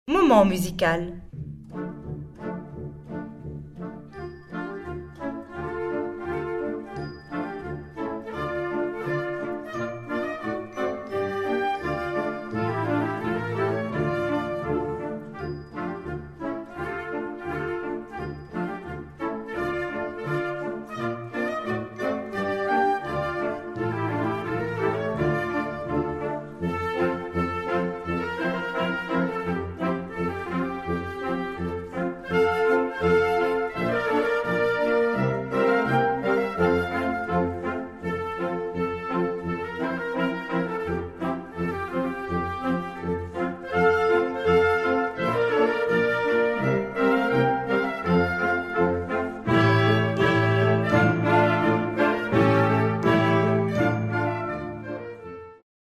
Répertoire pour Orchestre